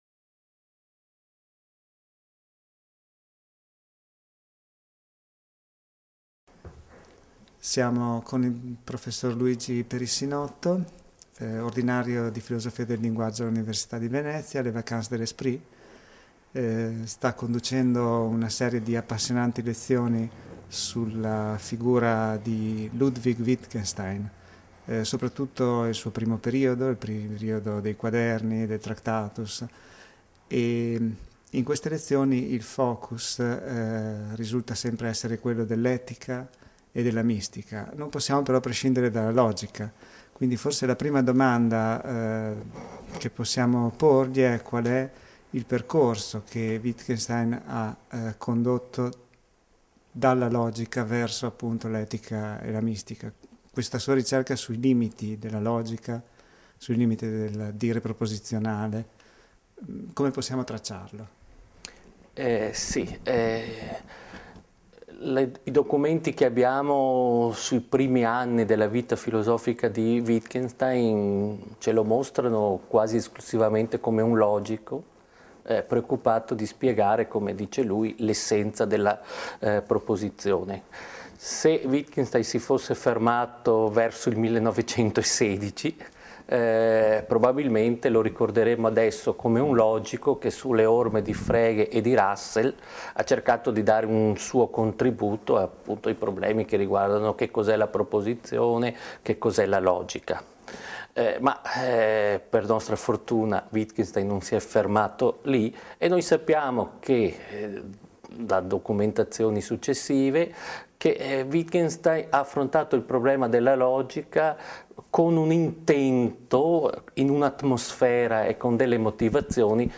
In occasione delle Vacances de l'Esprit autunnali del 2007